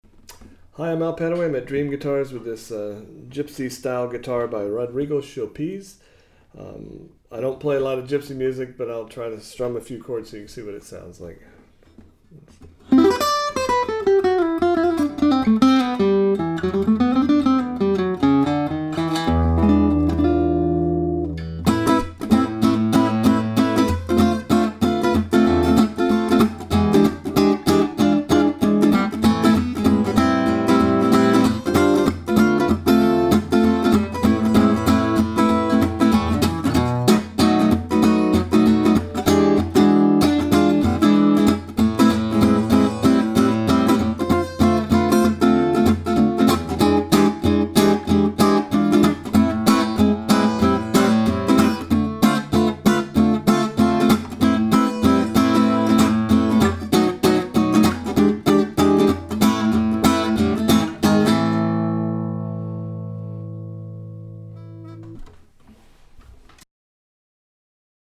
The top is French Polished allowing all the sound to come out. It features the characteristic “Petite Bouche” oval soundhole, floating bridge with brass tailpiece, and the long 667 mm scale length.